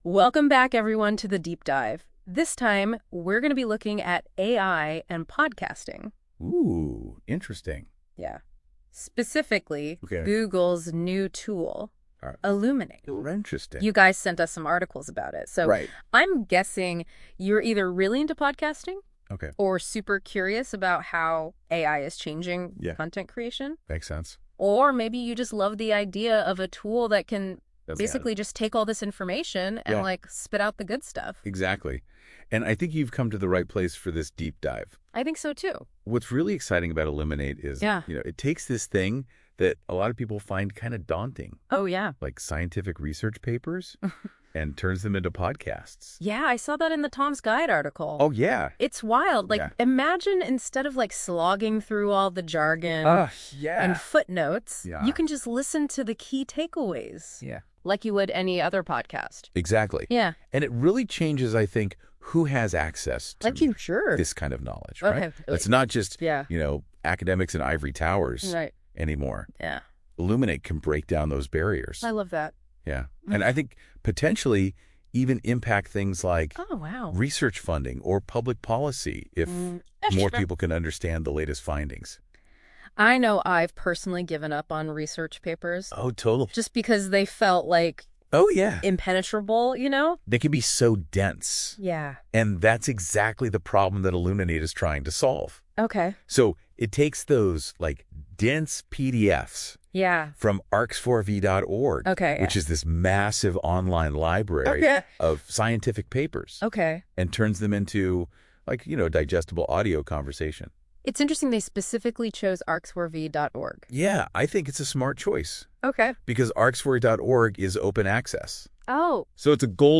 Listen to the podcast version of this blog post, created with Notebook LM, for deeper insights and practical takeaways: